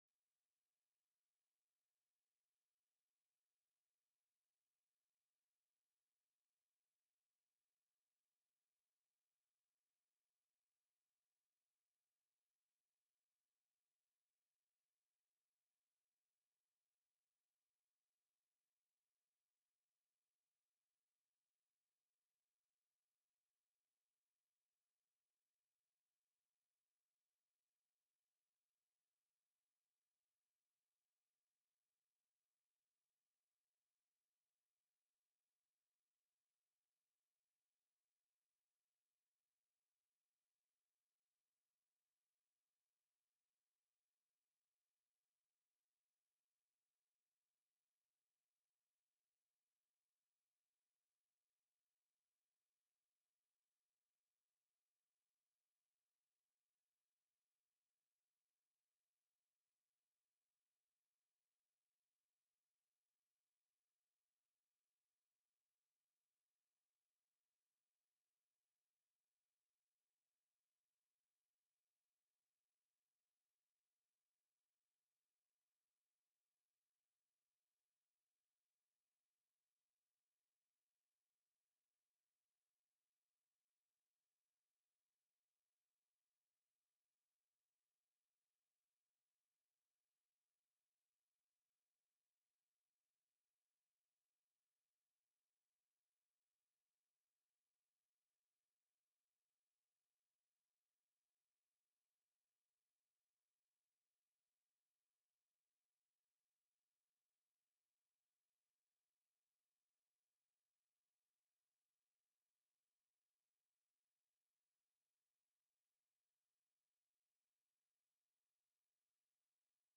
05/18/2025 01:00 PM House CONFERENCE COMMITTEE ON HB53 AND HB55
The audio recordings are captured by our records offices as the official record of the meeting and will have more accurate timestamps.